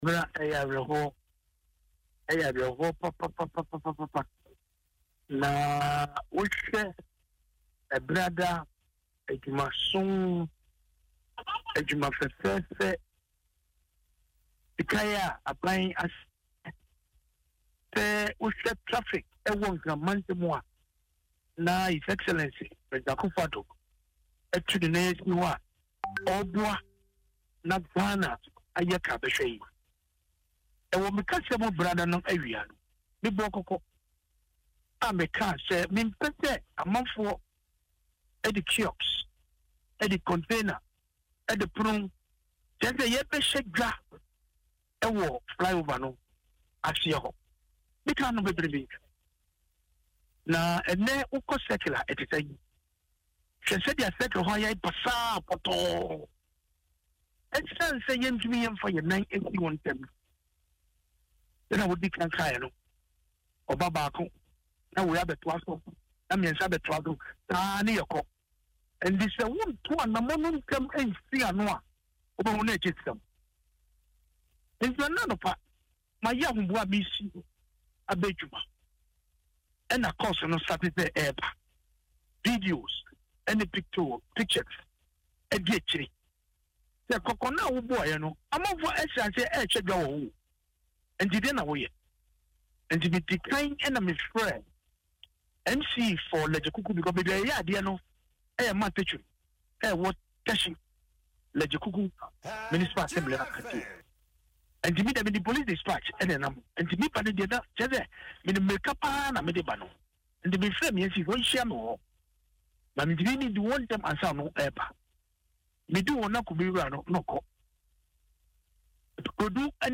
Mr. Glover sounded this message of caution on Adom FM’s Dwaso Nsem, following the commissioning on Tuesday.